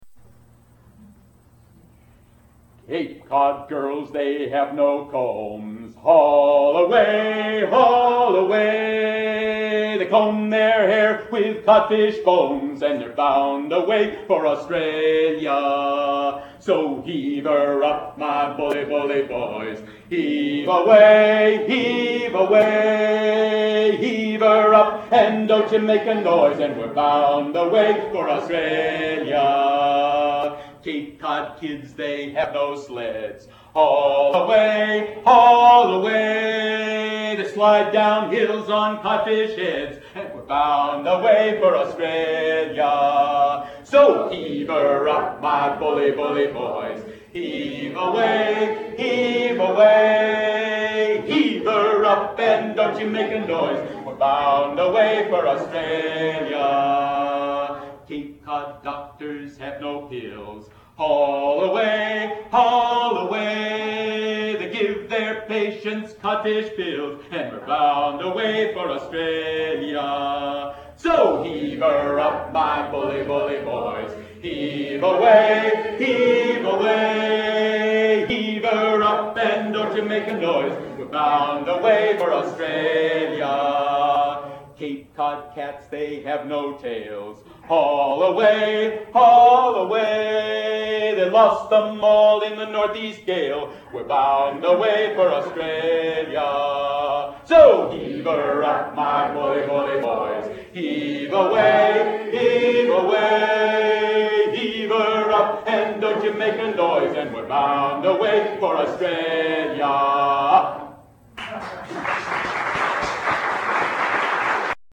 The Sea Shanty, Cape Cod Girls, is in The Boy Who Was Generous with Salt.